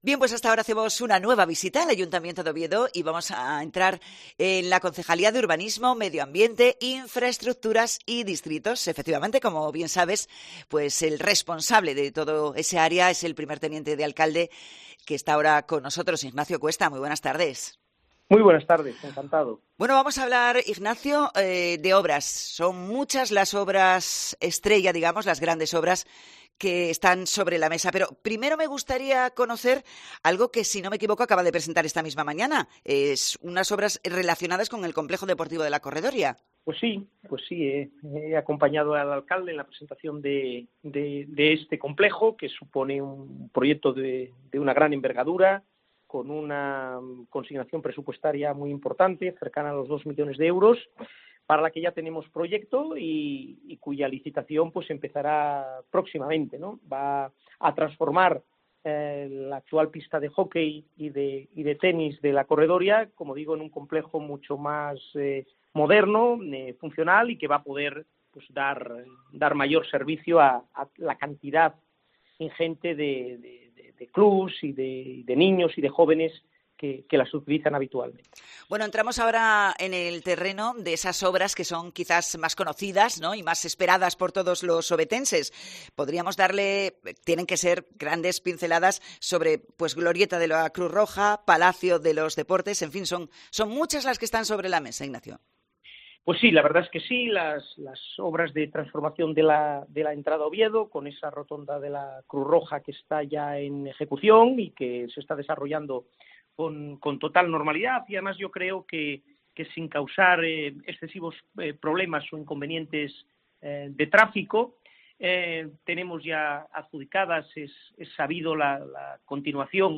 Entrevista a Nacho Cuesta, concejal de Urbanismo, Medio Ambiente, Infraestructuras y Distritos de Oviedo